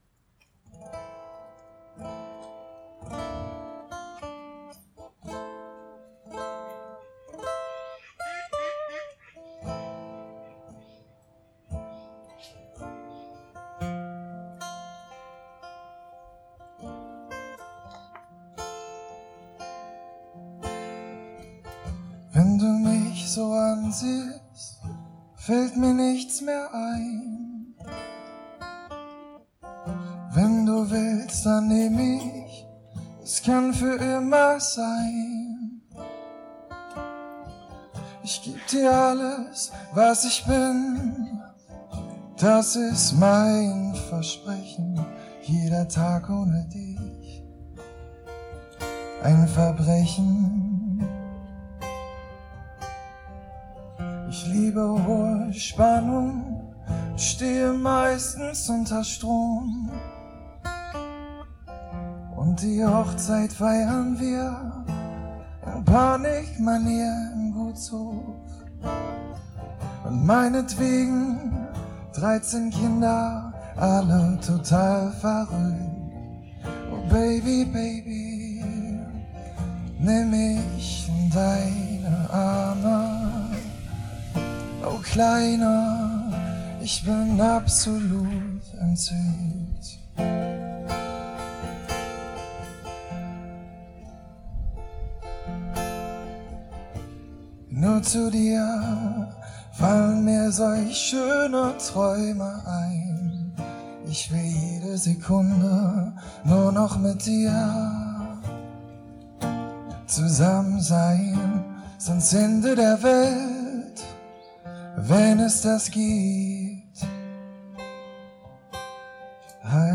Hochzeit 2024